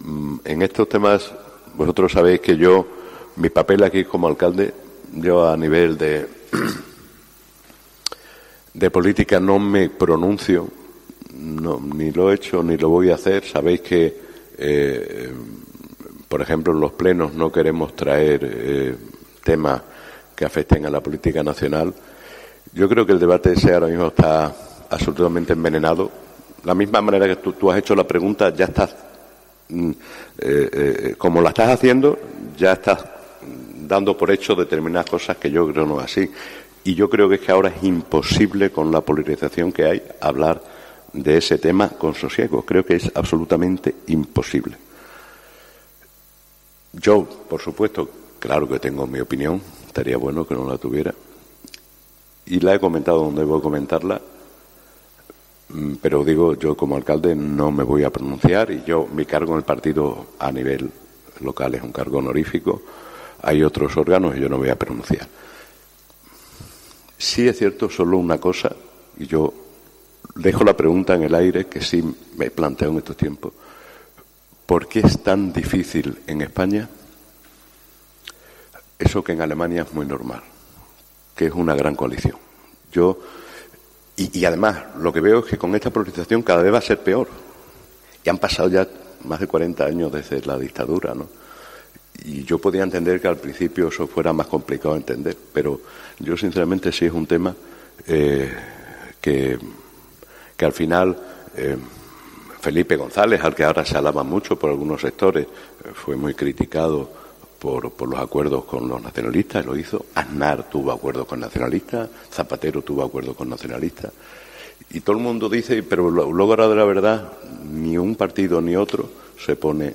COPE le ha preguntado al alcalde de Almendralejo, José María Ramírez, sobre su postura sobre la ley de amnistía.